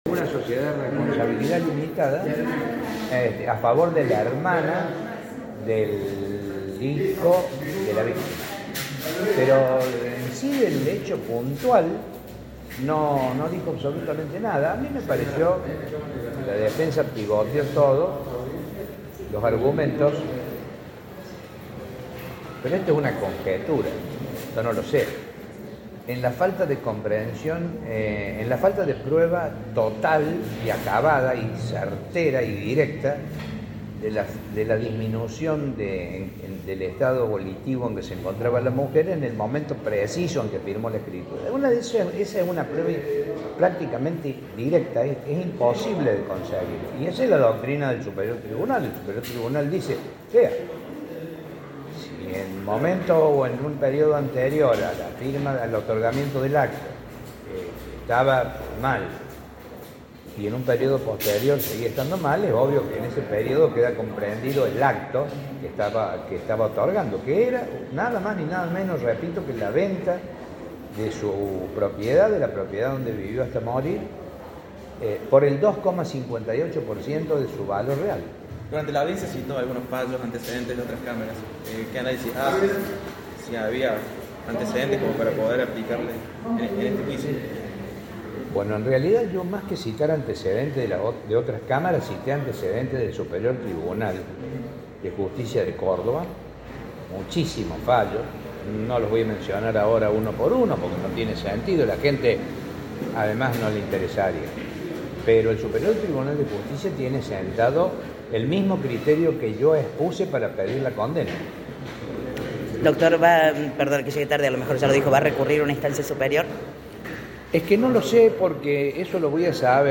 Audio: declaraciones